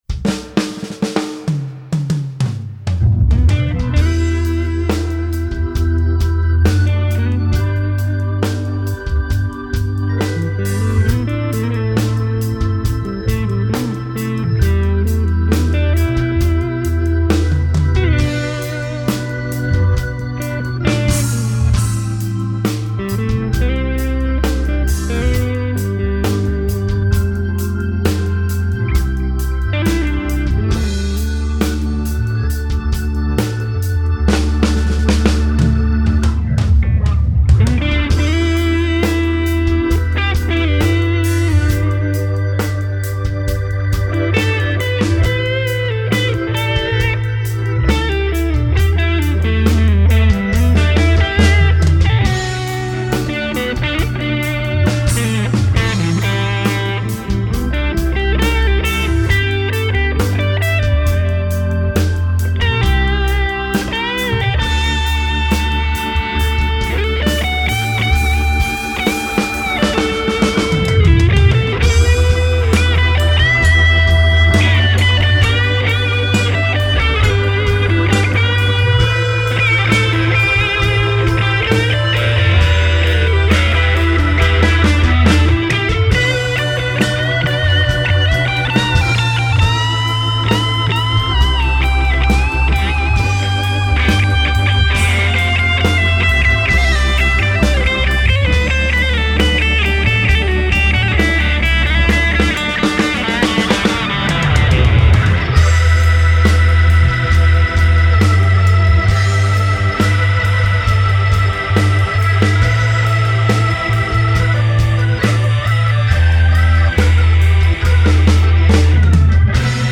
5 e 6) organo stereo
7) chitarra